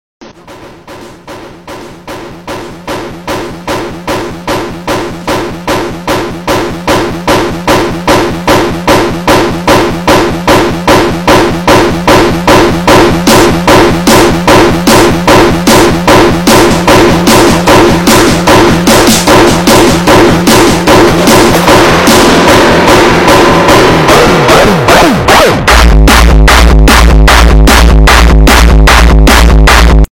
MI LAPTOP GAMER 💀 Así suena cuando apenas abro OBS… parece que va a despegar 🚀🔥